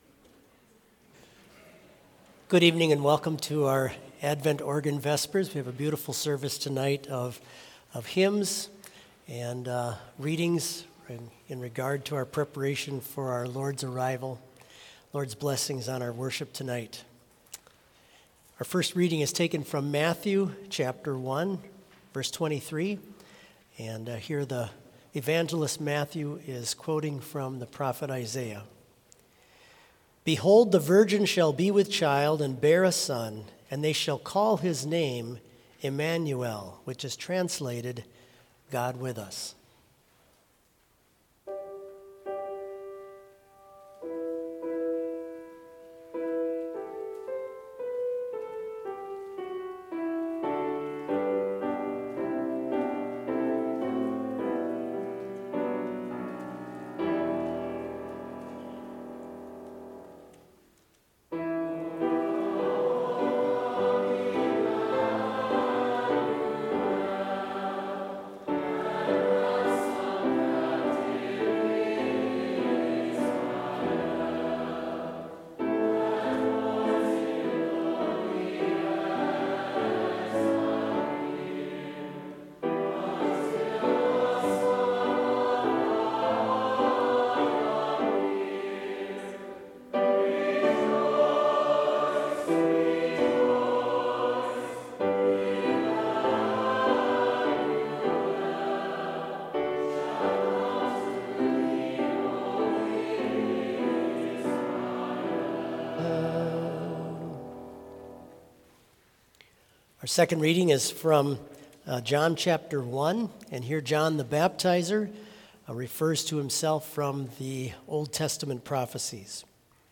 Advent in Trinity Chapel, Bethany Lutheran College
Complete service audio for Advent - Wednesday, December 6, 2023